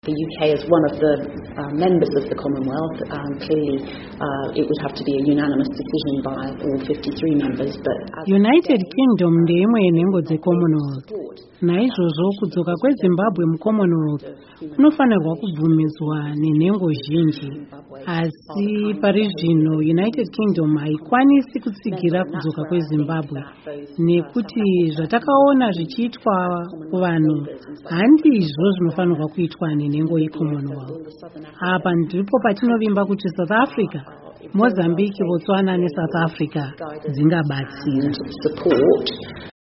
Amai Harriett Baldwin Vanotaura Nezve Zimbabwe neCommonwealth